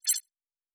pgs/Assets/Audio/Sci-Fi Sounds/Interface/Error 06.wav at master
Error 06.wav